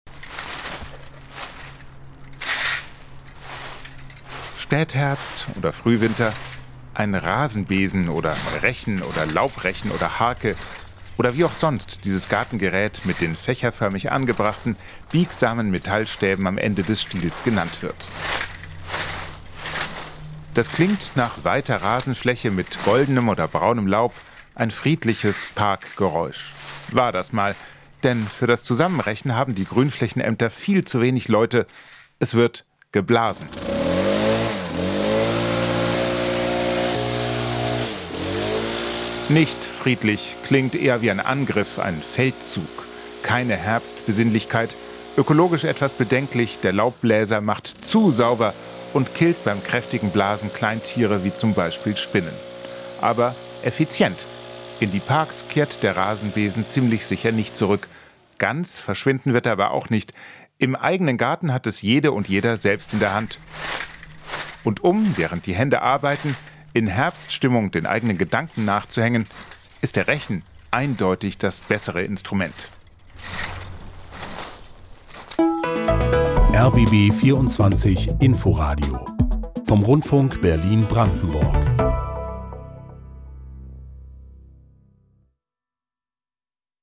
Geräusche, die verschwinden: Der Rechen